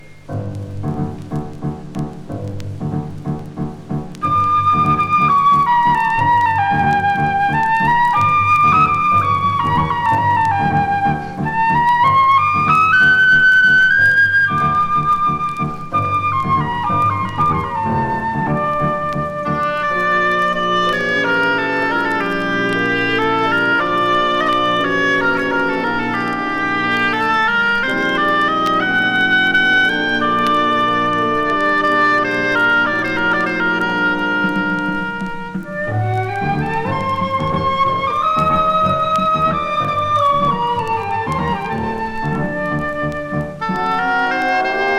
Classical, Easy Listening, World　Japan　12inchレコード　33rpm　Mono